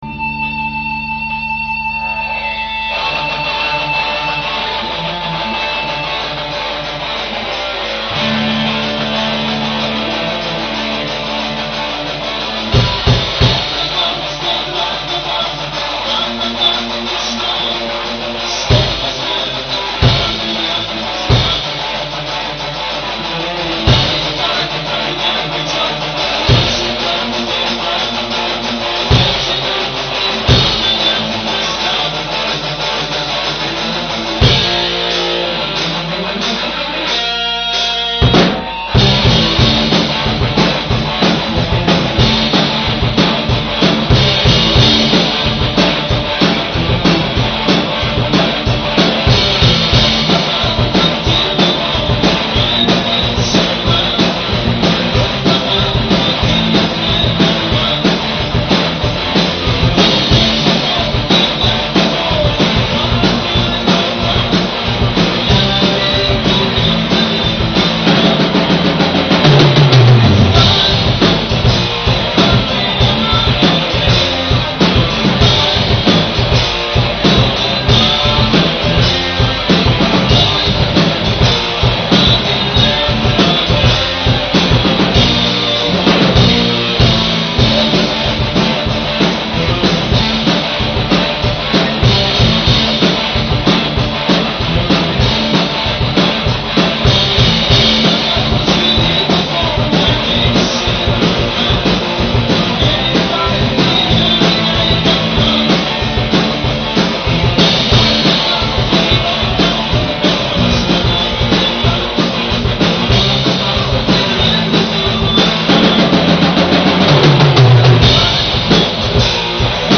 Запись с репетиций